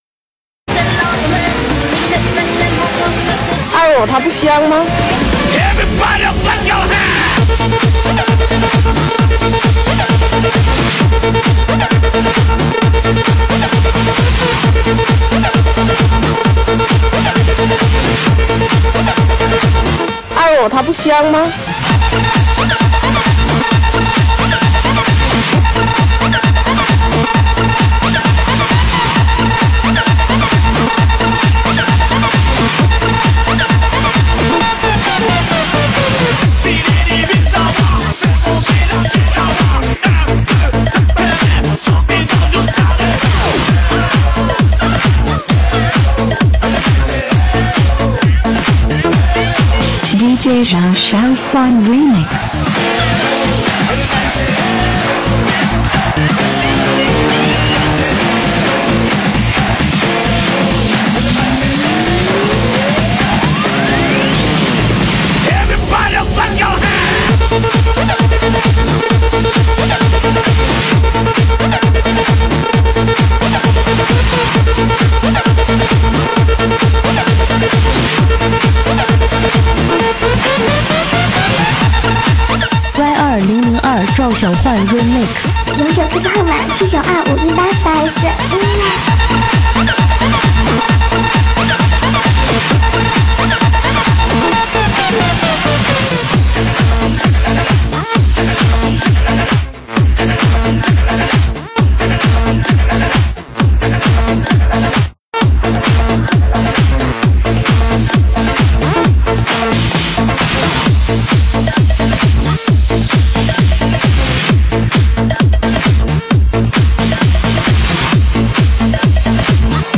本铃声大小为1197.9KB，总时长139秒，属于DJ分类。